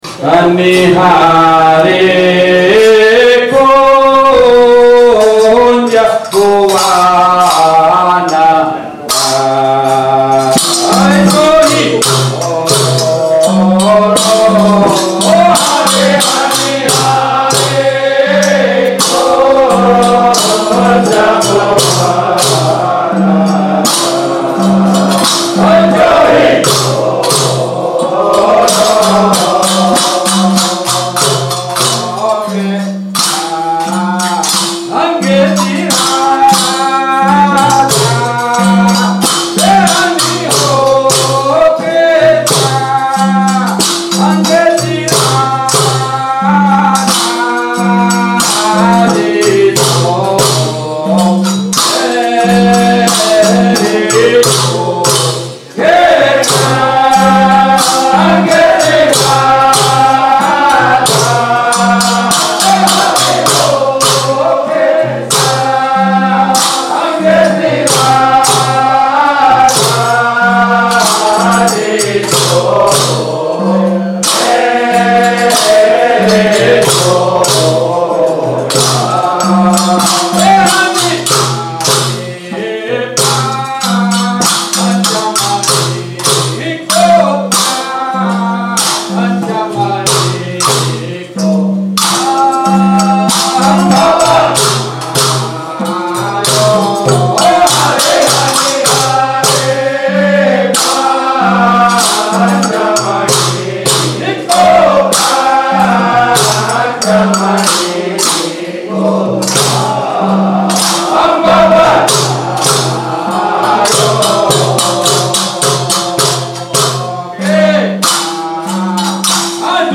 નાદબ્રહ્મ પદ - ૫૭૬, રાગ - હિંડોળાની સામેરી હનિહાંરે કુંજભુવન ...